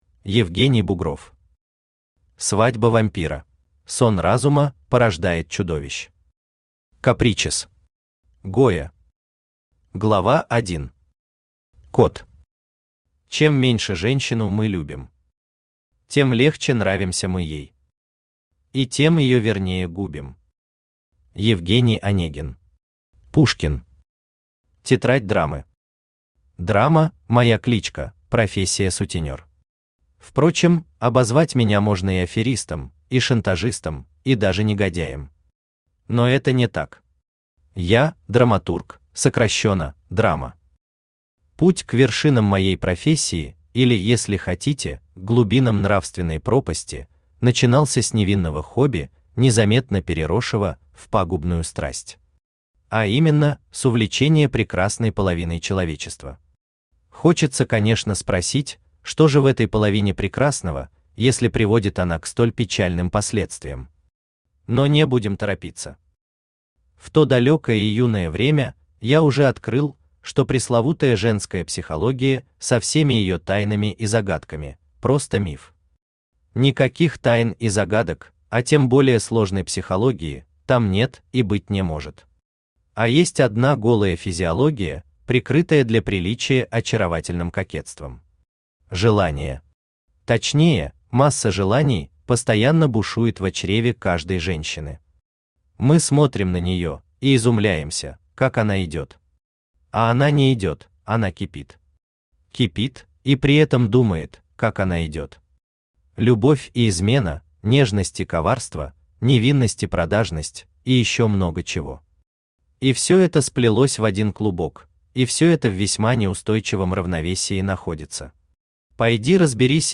Аудиокнига Свадьба вампира | Библиотека аудиокниг
Aудиокнига Свадьба вампира Автор Евгений Бугров Читает аудиокнигу Авточтец ЛитРес.